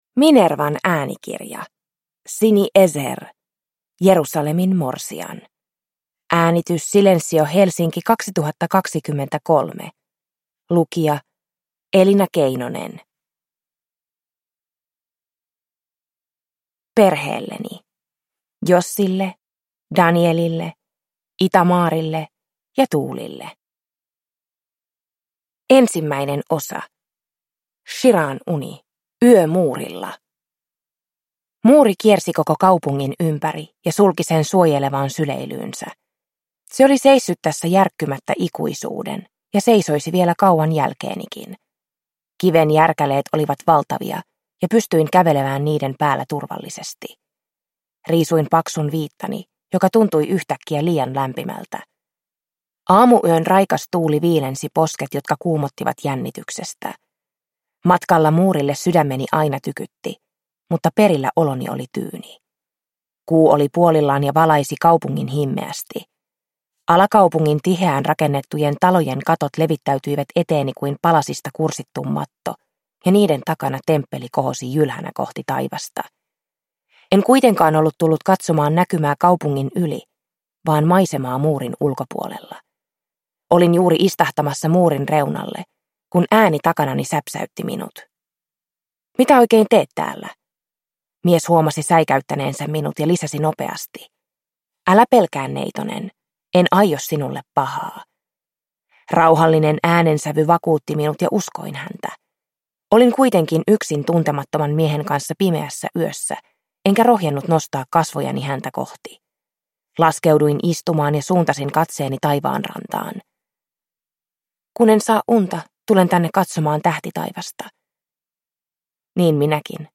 Jerusalemin morsian – Ljudbok – Laddas ner